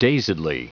Prononciation du mot dazedly en anglais (fichier audio)
dazedly.wav